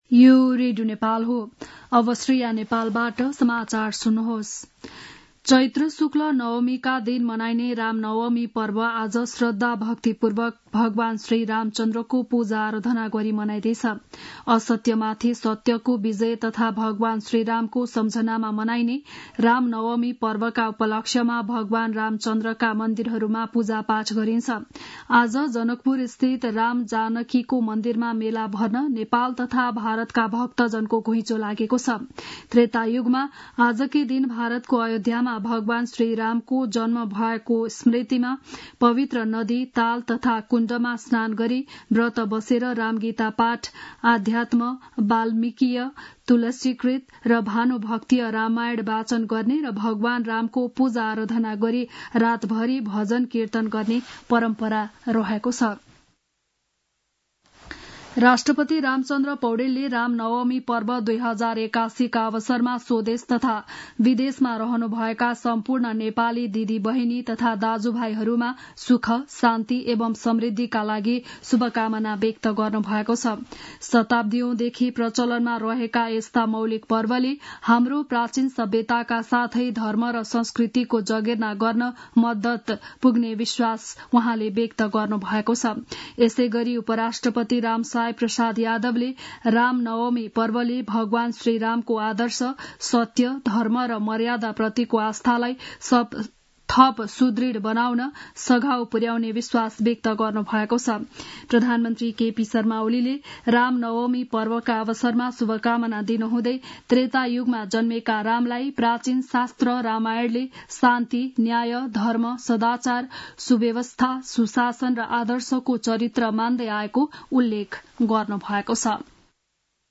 An online outlet of Nepal's national radio broadcaster
बिहान ११ बजेको नेपाली समाचार : २४ चैत , २०८१
11-am-news-1-1.mp3